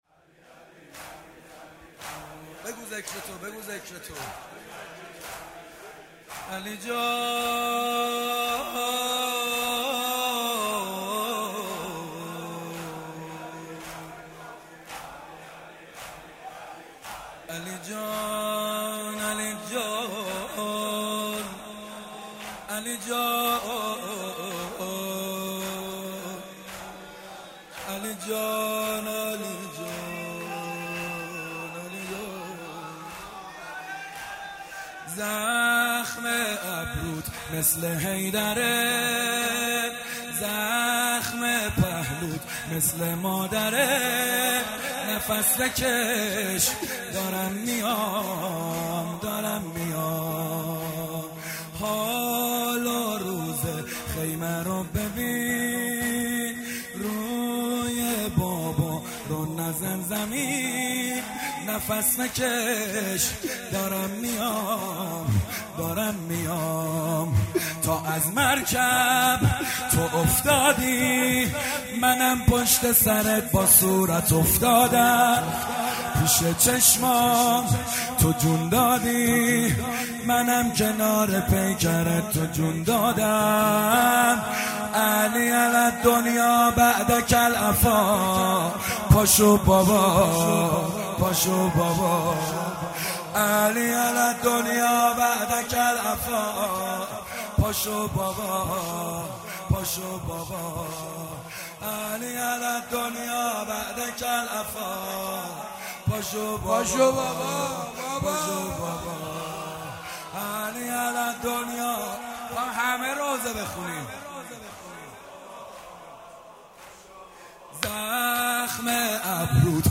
شور عربی